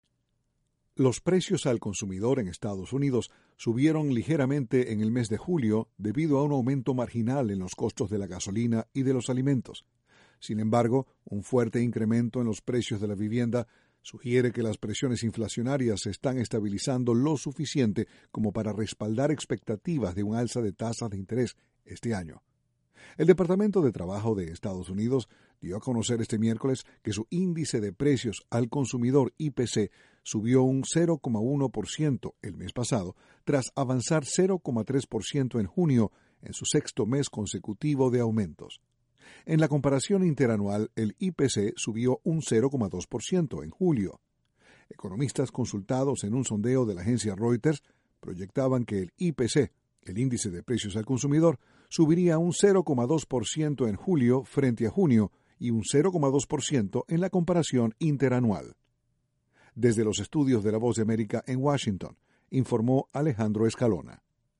Noticias sobre economía de Estados Unidos